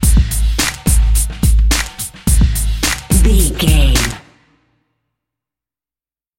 Aeolian/Minor
drum machine
synthesiser
hip hop
soul
Funk
energetic
cheerful
bouncy
funky